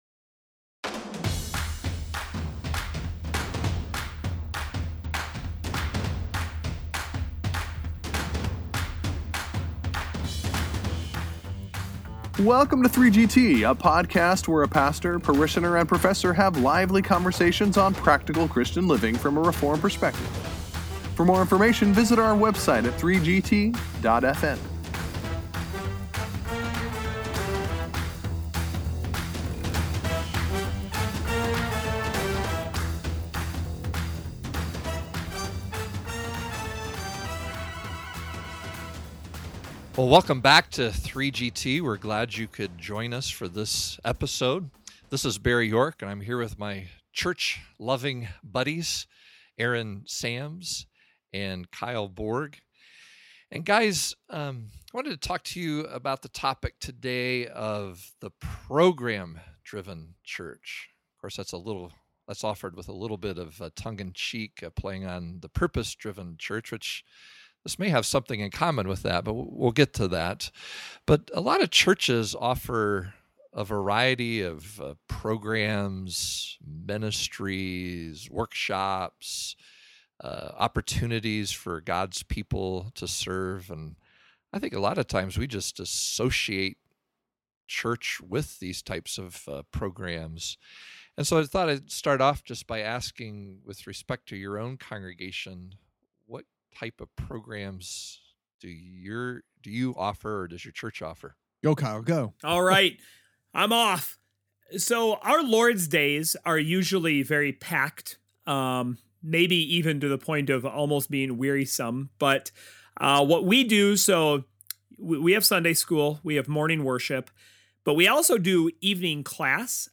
The 3GTers answer this question, then converse about the pros and cons of a congregation offering various activities, events, and ministries.